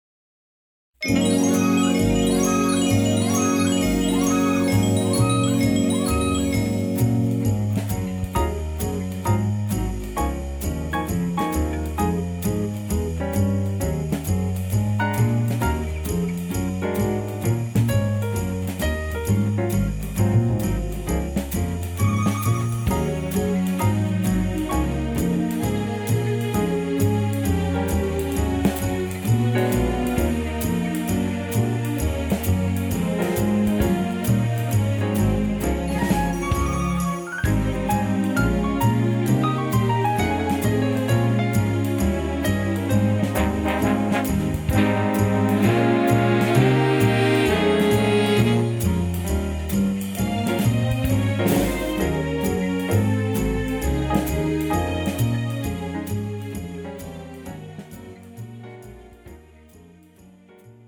MR 고음질 반주